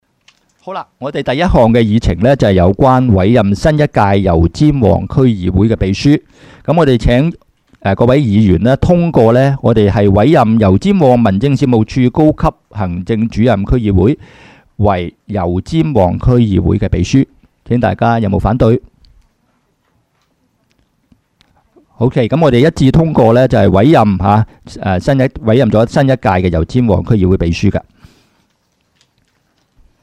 区议会大会的录音记录
油尖旺区议会会议室